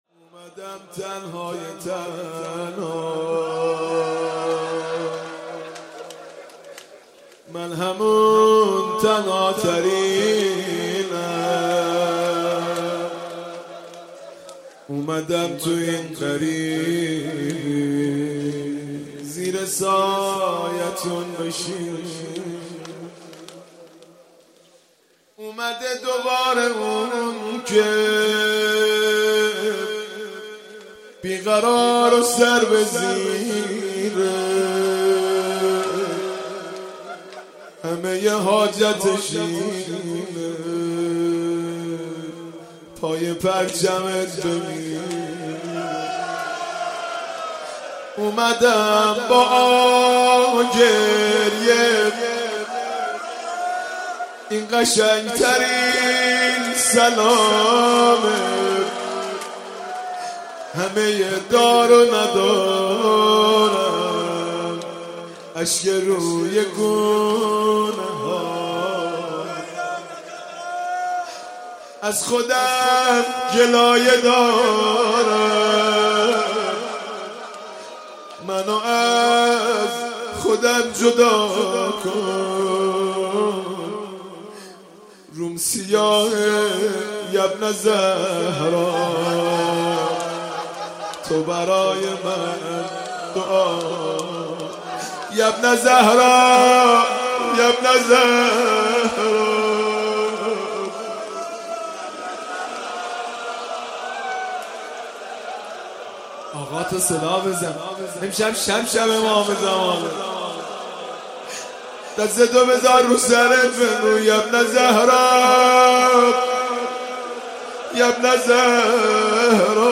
مداحی
شب چهارم ماه رمضان